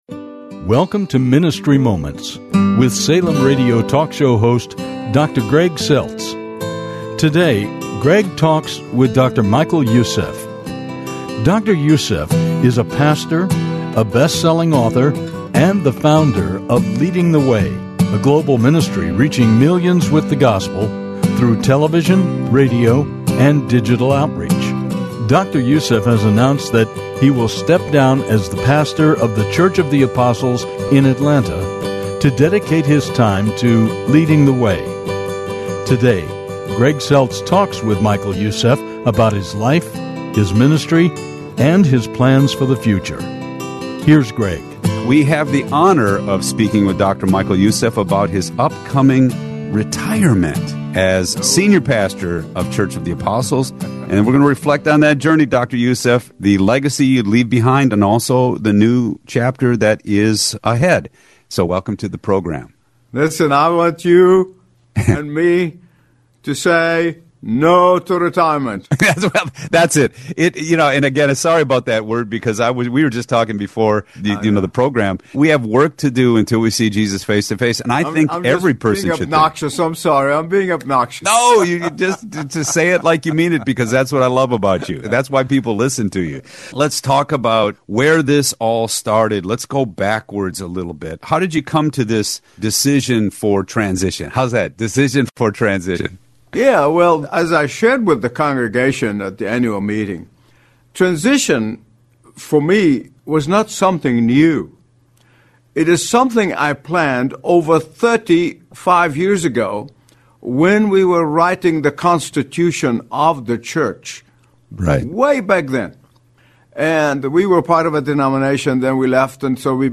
Listen to a bold, Spirit-led conversation rooted in Biblical Truth and Gospel urgency